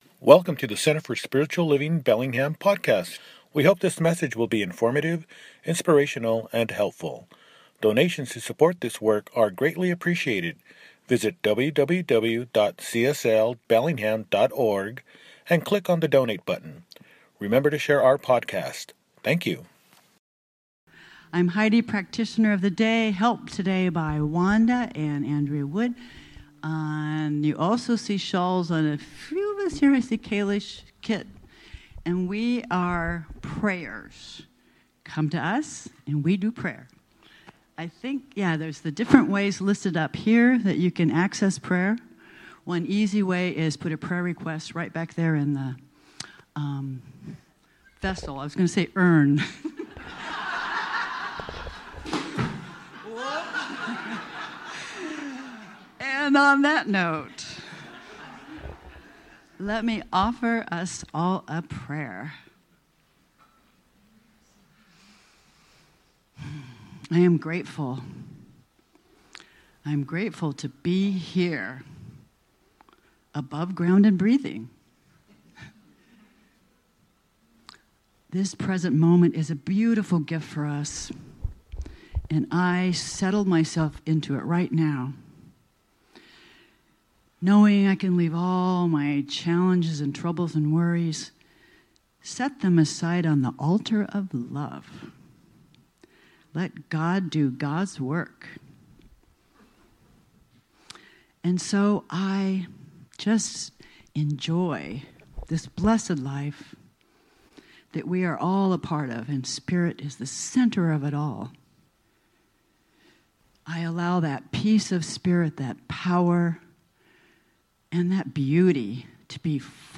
Be Yourself, Everyone Else is Taken! – Celebration Service | Center for Spiritual Living Bellingham